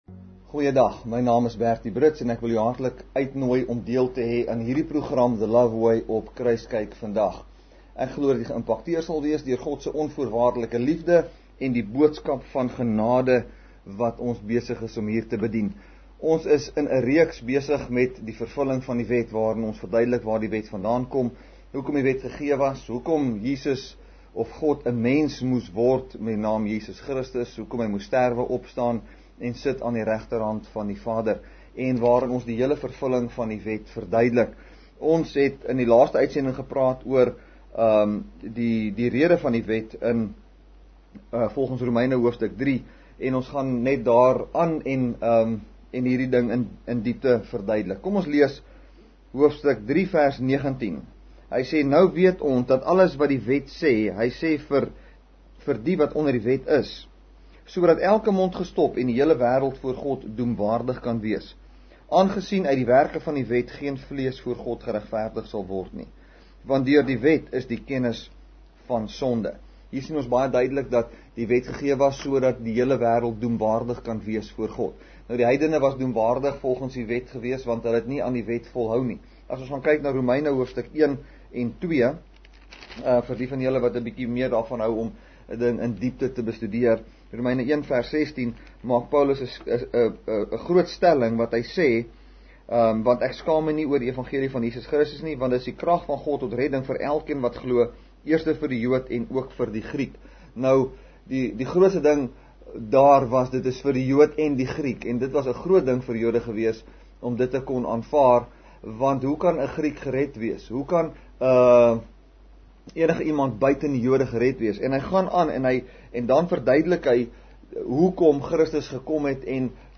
May 24, 2016 | TV BROADCASTING | Kruiskyk Uitsendings